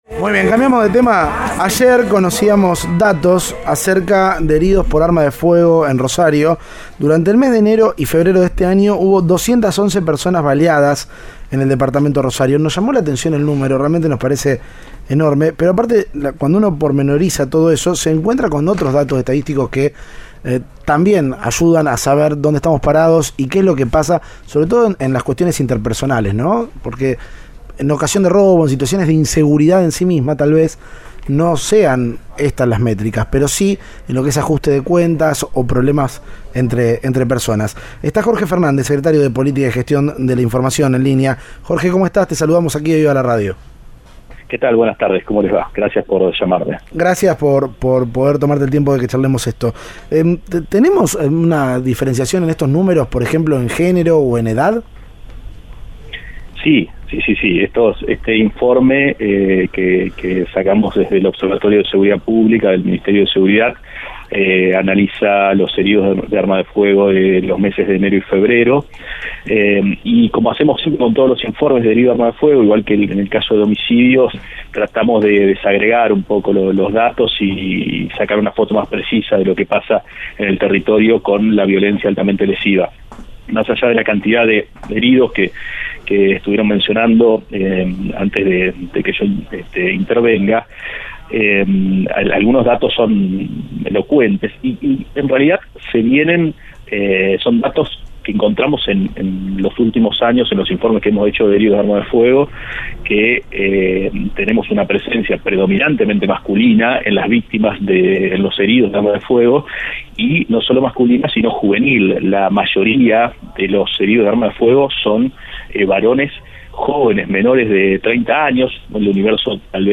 El secretario de Gestión de Información dio detalles en Cadena 3 Rosario.